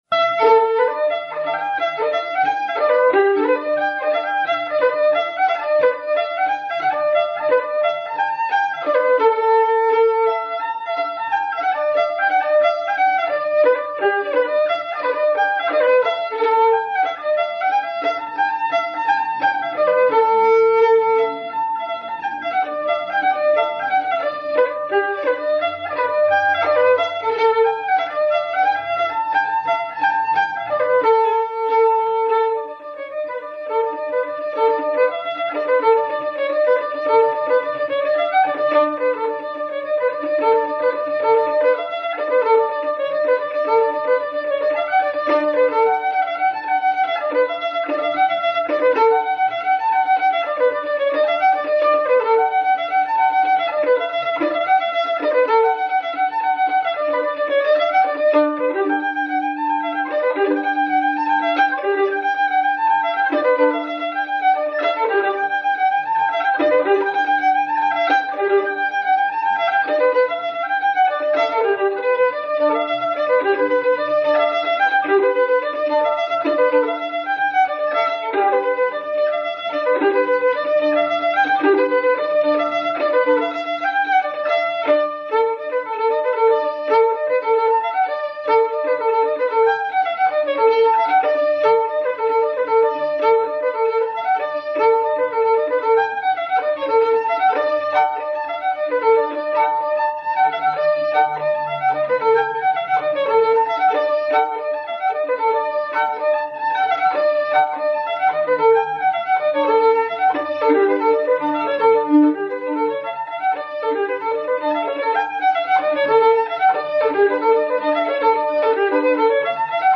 Fiddler 1910 – 1985
From a home-made recording, Inverkeithing, undated.